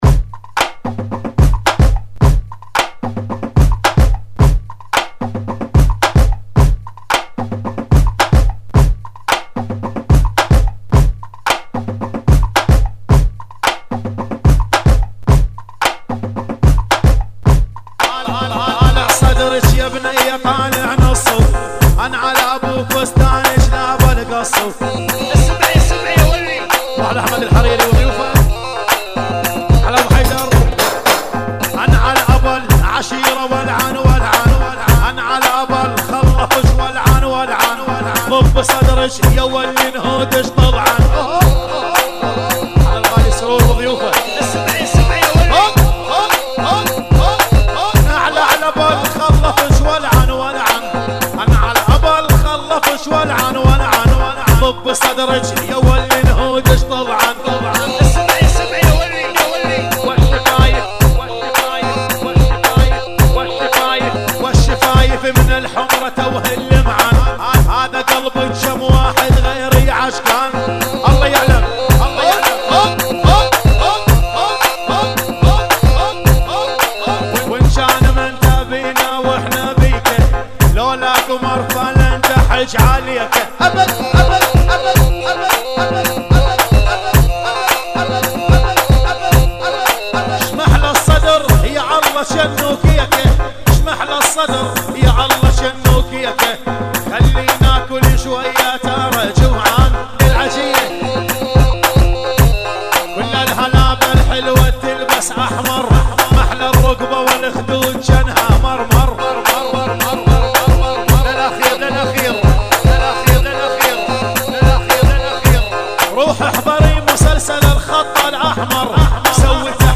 [ 110 Bpm ]